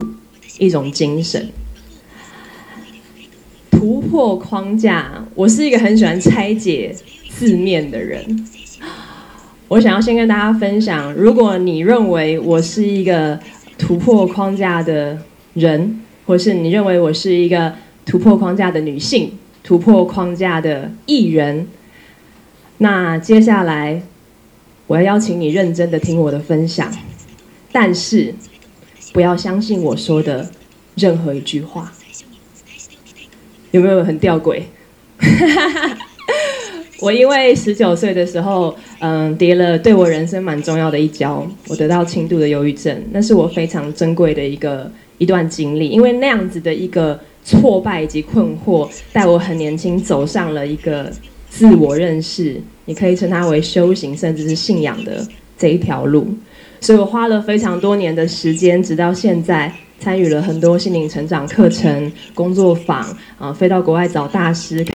レビュー時には試しに、さまざまな分野の著名人による講演を視聴できる「TED（Technology Entertainment Design）」の音声を、この傍聴通訳モードで翻訳させてみた。
録音データでは、イヤホンからの通訳音声が裏でかすかに聴こえるので、注意して聴いてみてほしい。